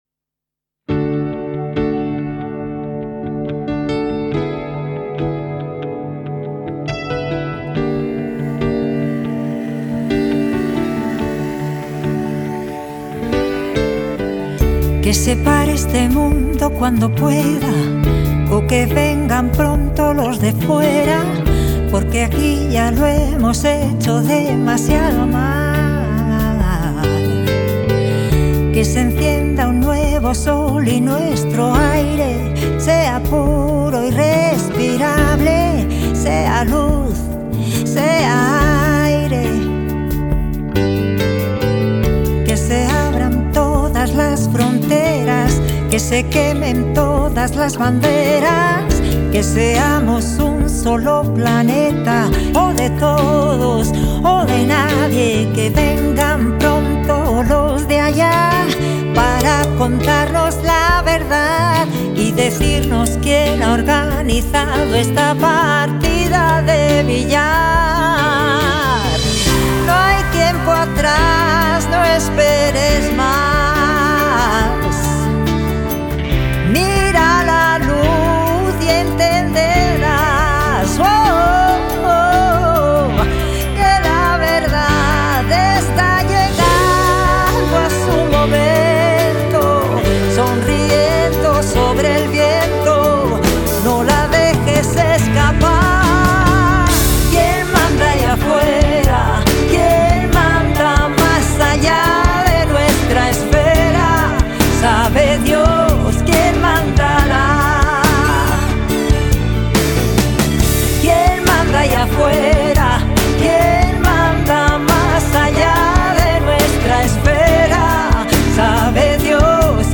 Spain • Genre: Pop